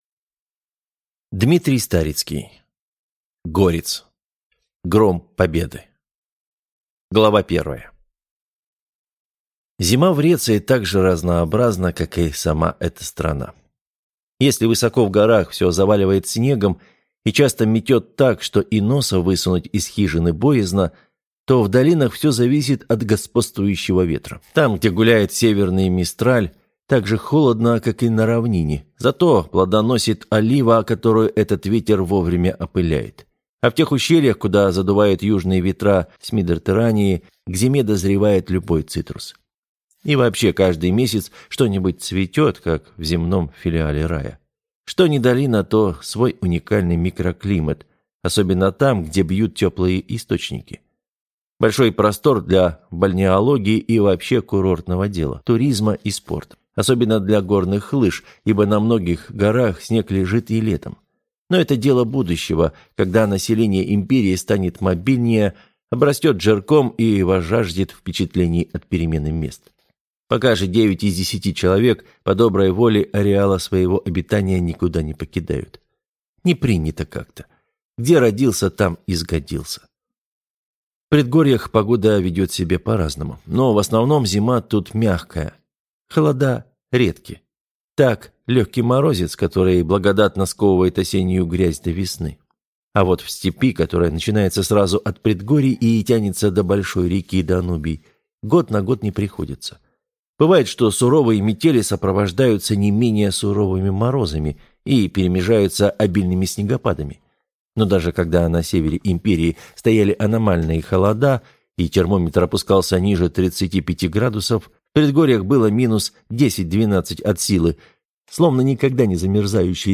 Аудиокнига Горец. Гром победы - купить, скачать и слушать онлайн | КнигоПоиск
Аудиокнига «Горец. Гром победы» в интернет-магазине КнигоПоиск ✅ Фантастика в аудиоформате ✅ Скачать Горец. Гром победы в mp3 или слушать онлайн